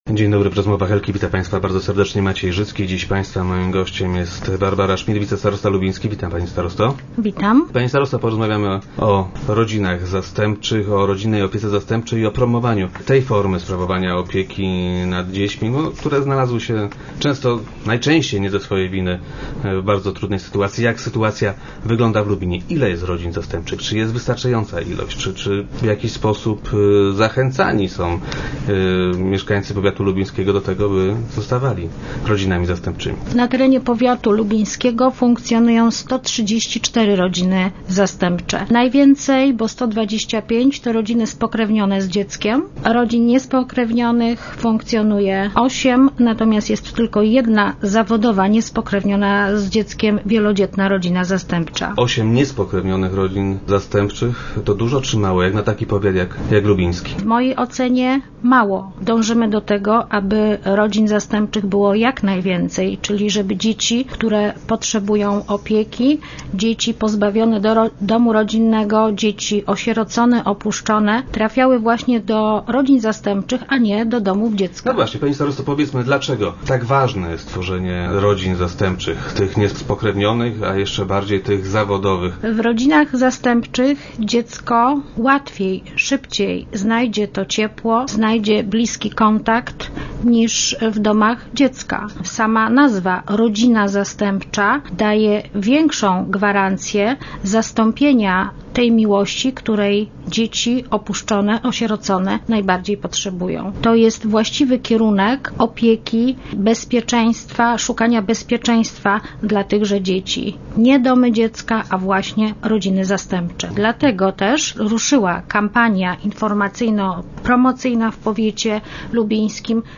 Gościem poniedziałkowych Rozmów Elki była wicestarosta Barbara Schmidt.